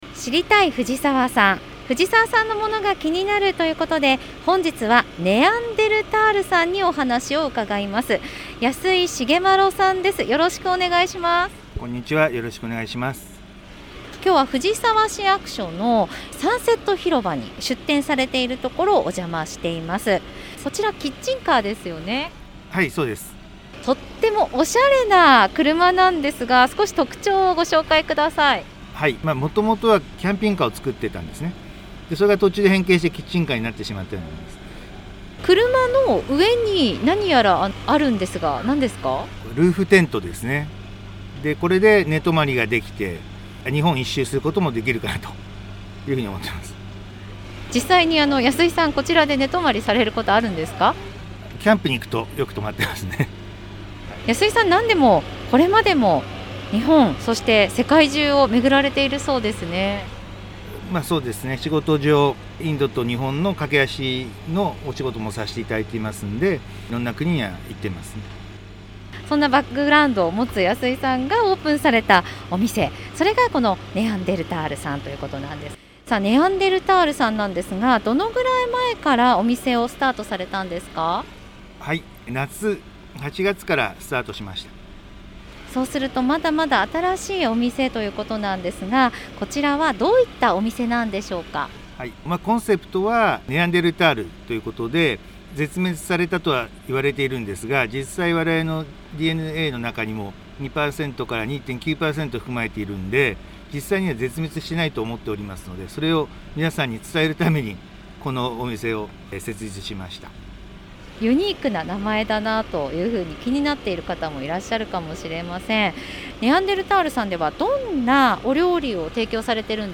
令和3年度に市の広報番組ハミングふじさわで放送された「知りたい！藤沢産」のアーカイブを音声にてご紹介いたします。